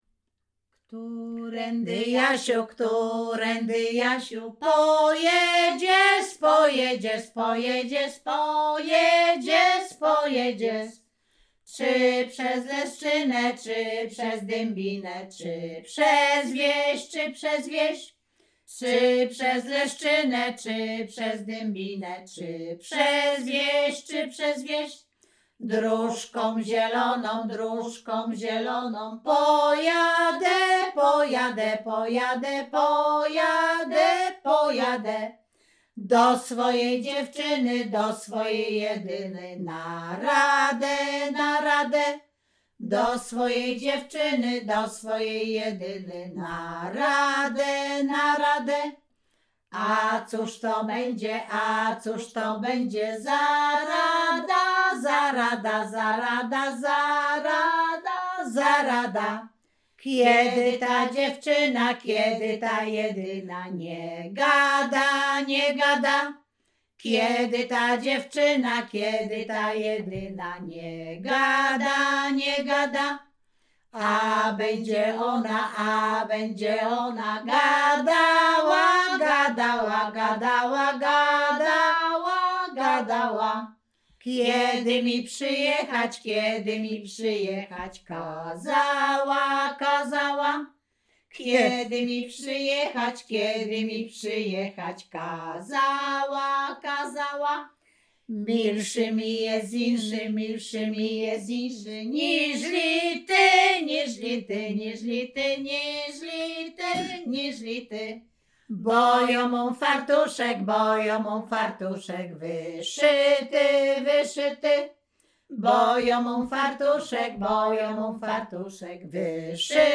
Śpiewaczki z Chojnego
Sieradzkie
województwo łódzkie, powiat sieradzki, gmina Sieradz, wieś Chojne
miłosne liryczne